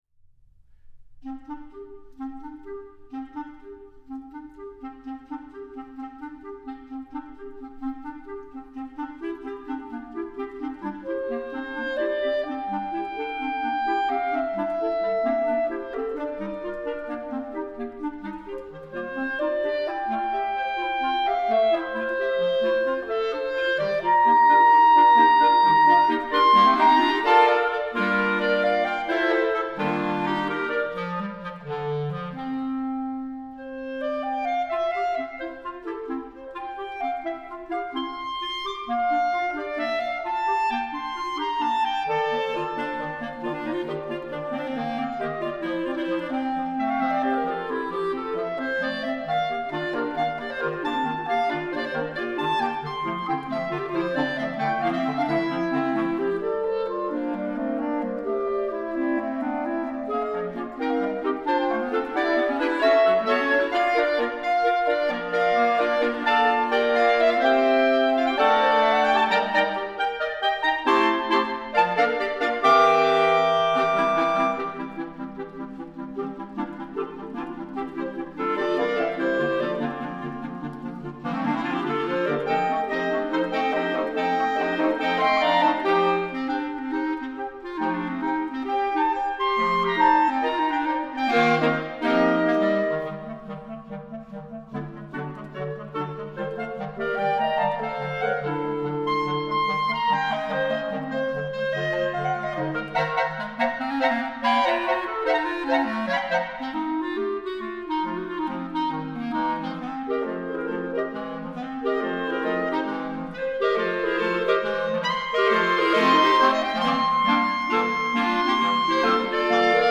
Noten für Klarinettenensemble, 7-stimmig.